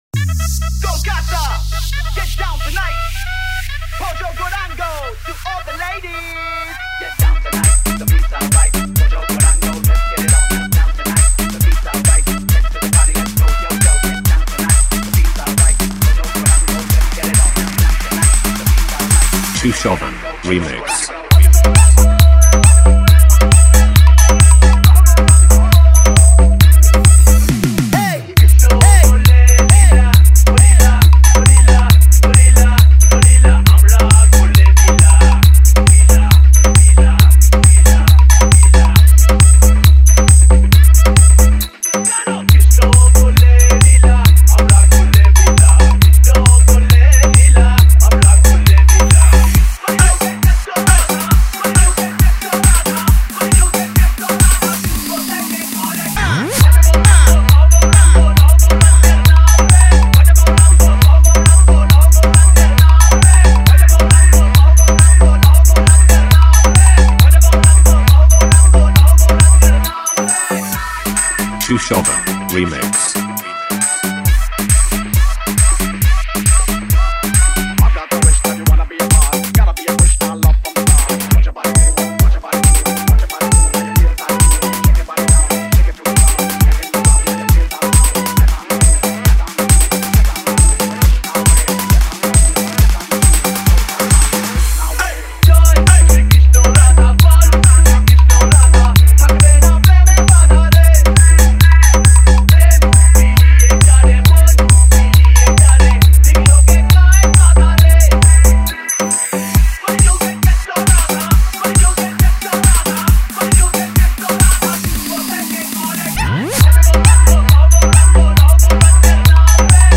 Lakshmi Puja SpL 1 Step Bhakti Song Wait Humming Mix 2024